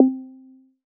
Bounce 1.wav